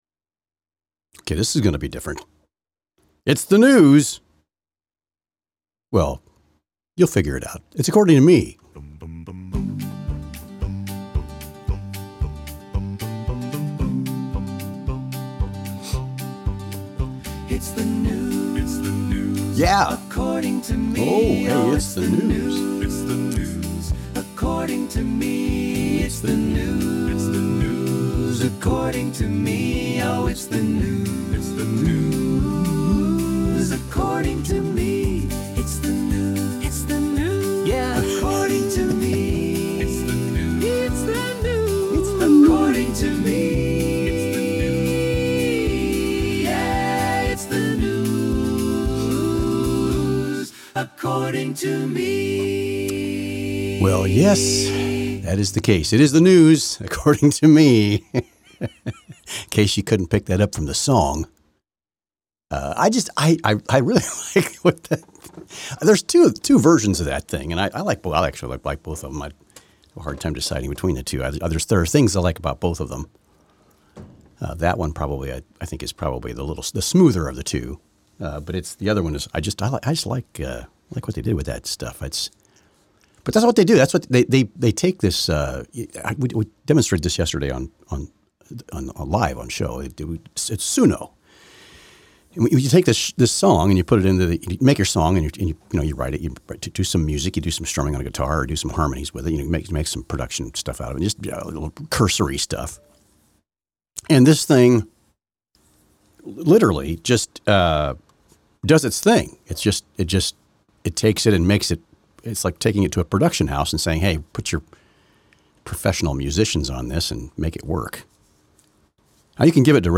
And we play with some music as well.